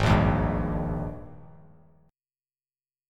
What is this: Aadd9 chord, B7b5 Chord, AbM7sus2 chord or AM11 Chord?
AbM7sus2 chord